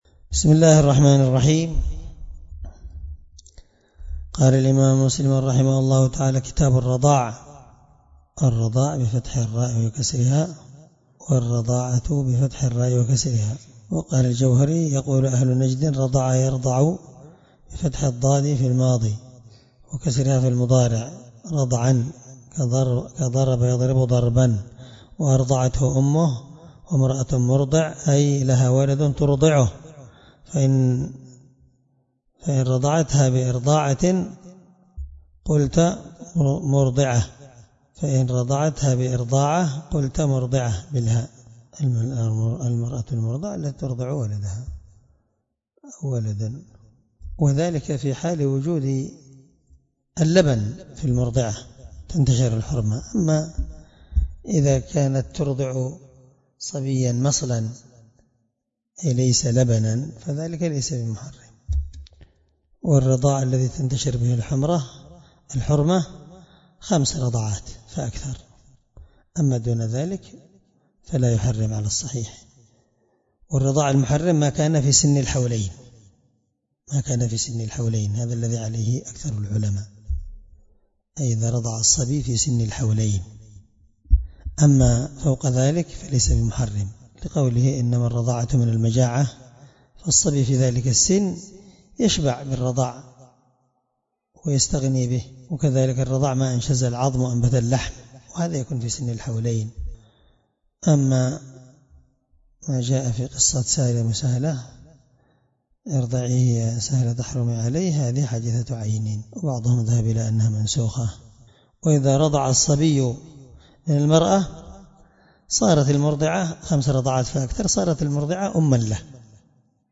الدرس1من شرح كتاب الرضاع حديث رقم(1444) من صحيح مسلم